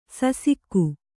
♪ sasikku